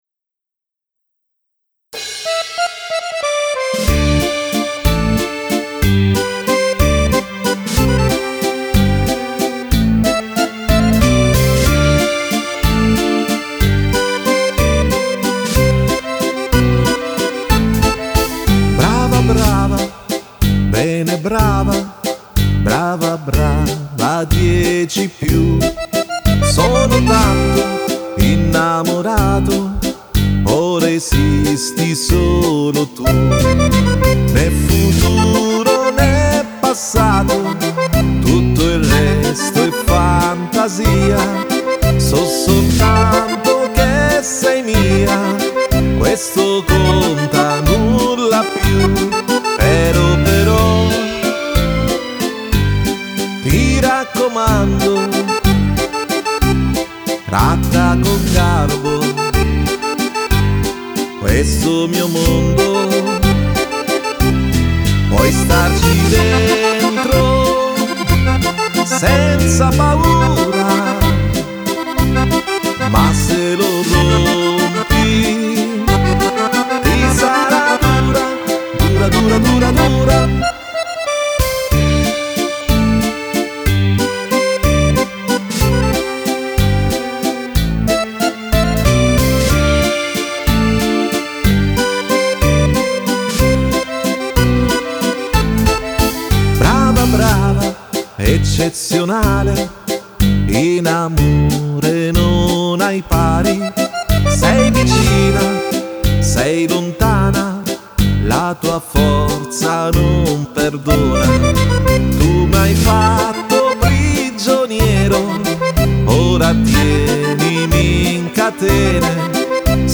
Valzer Canzone